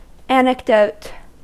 Ääntäminen
IPA: [ˌhɪsˈtuː rɪa]